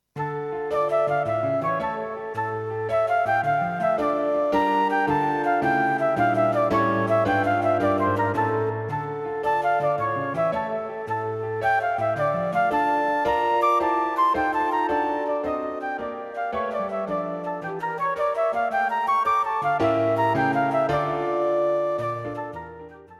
The audio clip is the third movement - Allegretto.